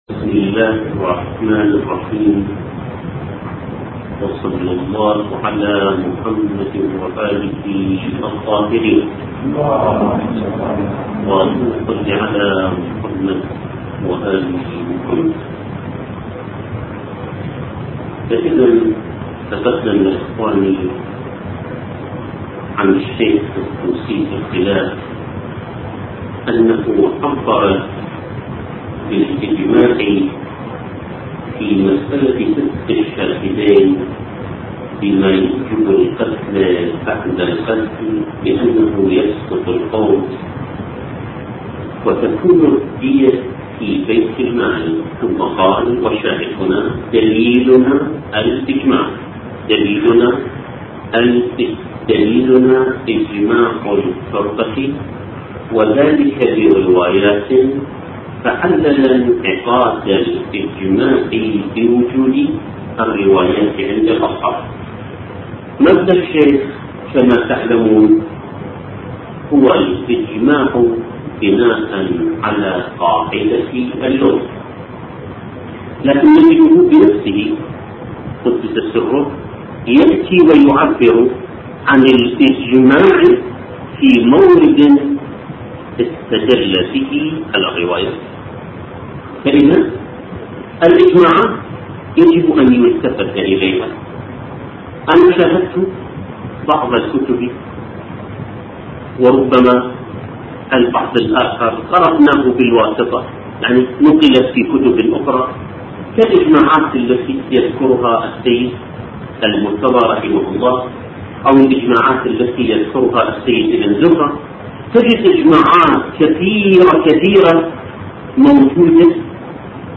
محاضرات اسلامية- محاضرات شيعية-نهضة الامام الحسين-تفسير القران الكريم-